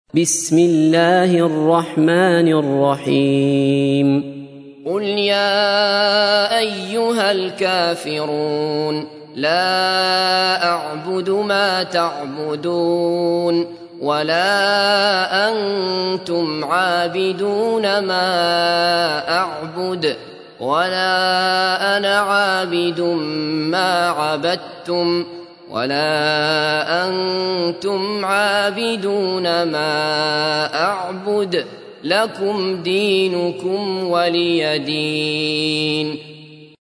تحميل : 109. سورة الكافرون / القارئ عبد الله بصفر / القرآن الكريم / موقع يا حسين